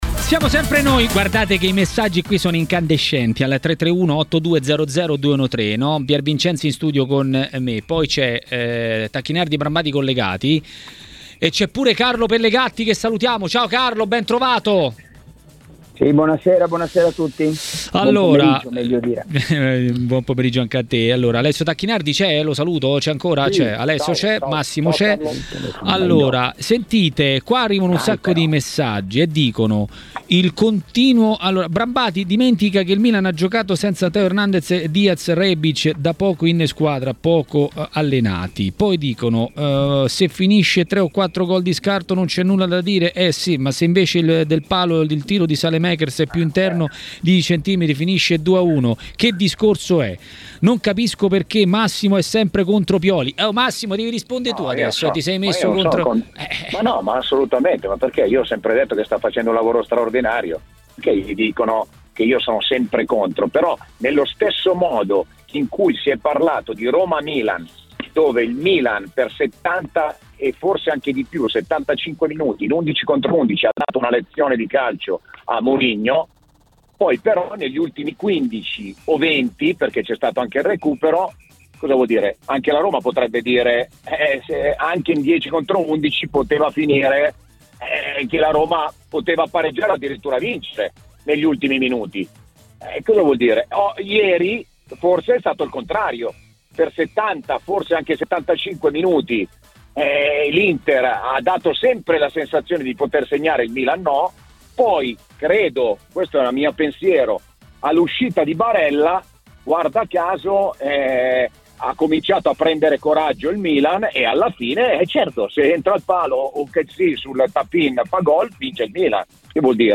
Alessio Tacchinardi, ex calciatore e tecnico, ha commentato il dodicesimo turno di Serie A, analizzando anche il derby.